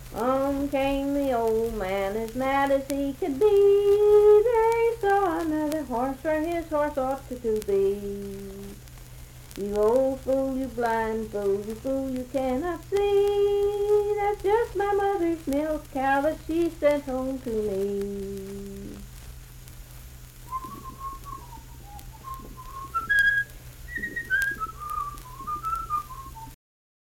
Unaccompanied vocal music
Marriage and Marital Relations, Humor and Nonsense, Bawdy Songs
Voice (sung)
Richwood (W. Va.), Nicholas County (W. Va.)